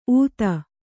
♪ ūta